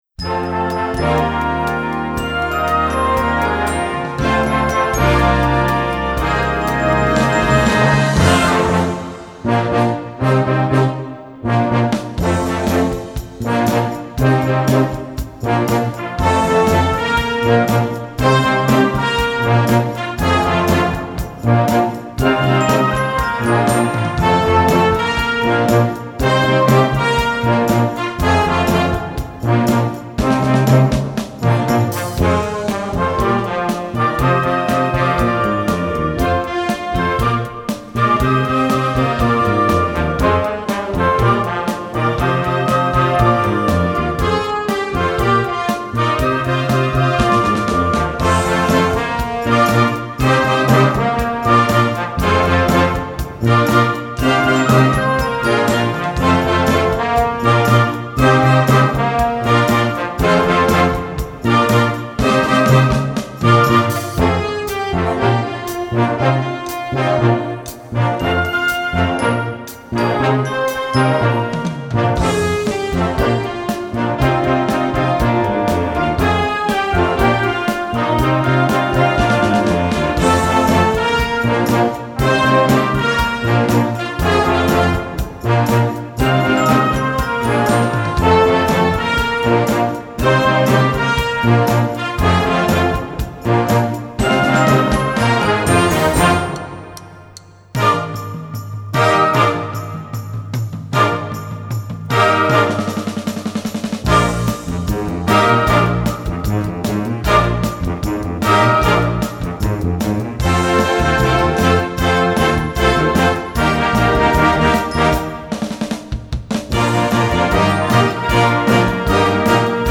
Voicing: Flex Band